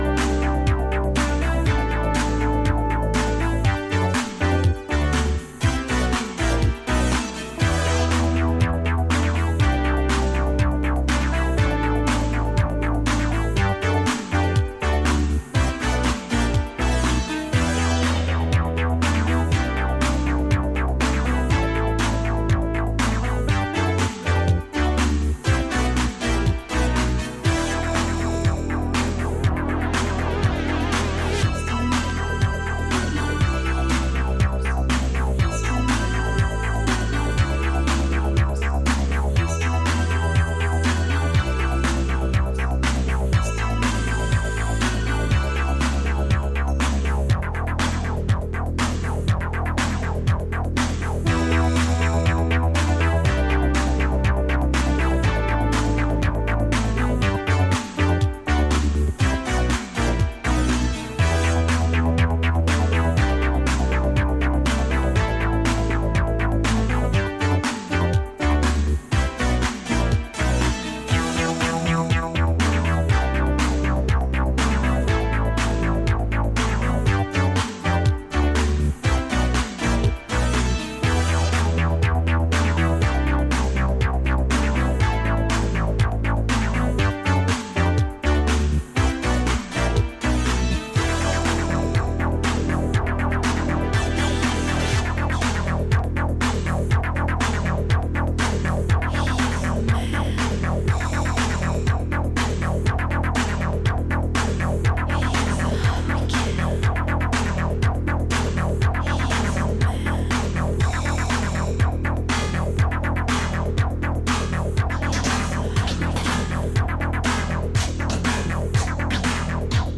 discofied covers
Italo